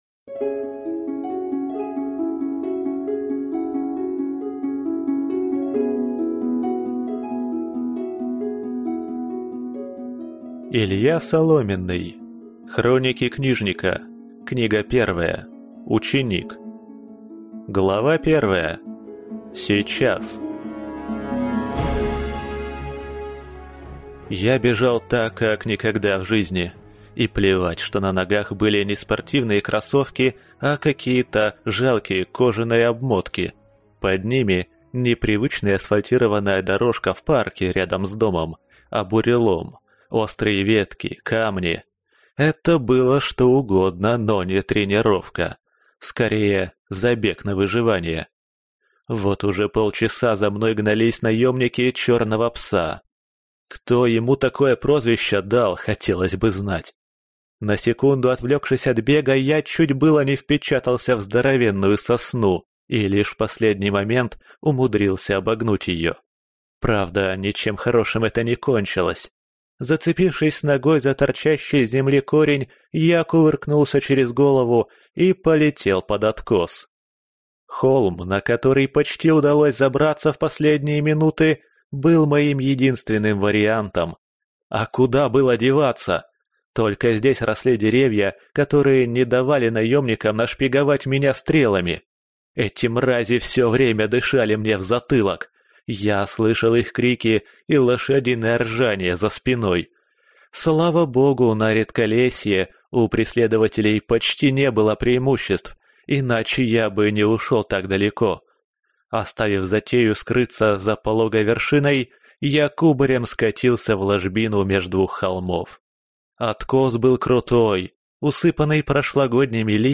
Аудиокнига Ученик | Библиотека аудиокниг